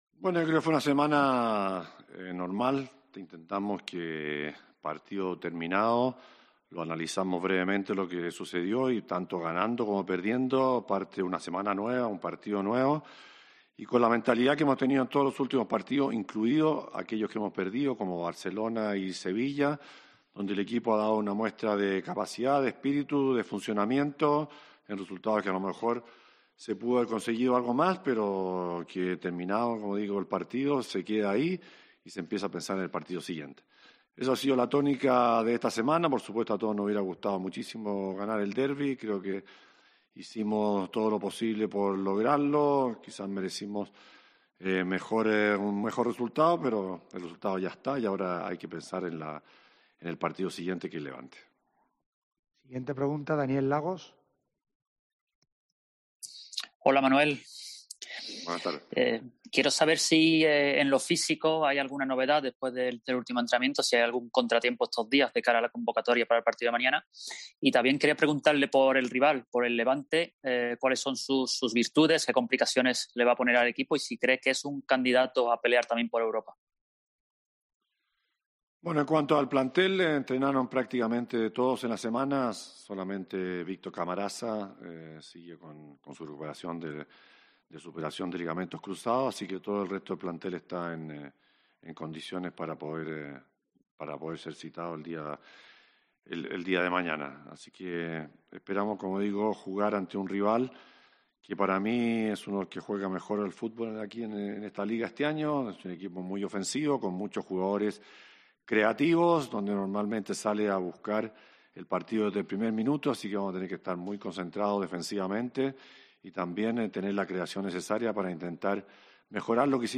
LA RUEDA DE PRENSA DE PELLEGRINI PREVIA AL PARTIDO ANTE EL LEVANTE
Aquí puedes escuchar la rueda de prensa completa del técnico.